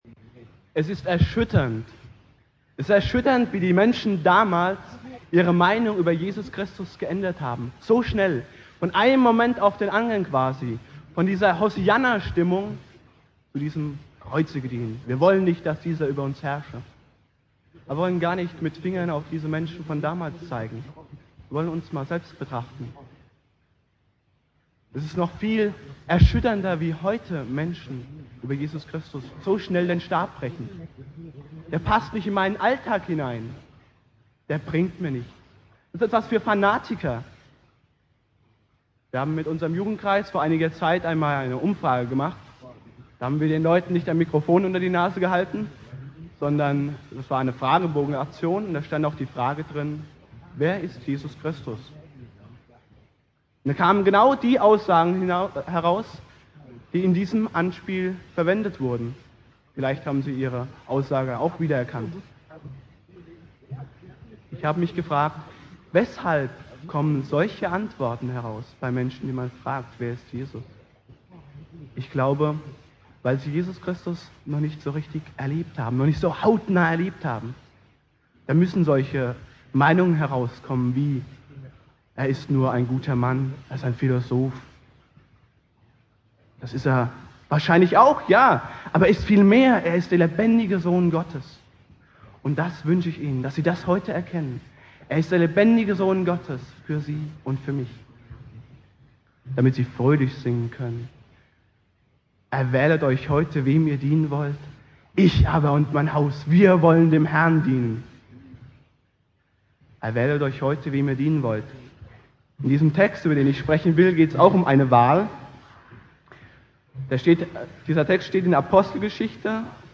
Thema: Erwählt Euch heute, wem Ihr dienen wollt - Josua 24,15 (Predigt im Jugendgottesdienst)